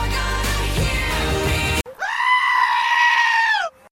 Roar Sheep
roar-sheep.mp3